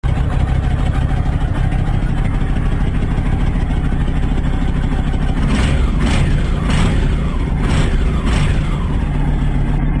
Auspuff-Sounds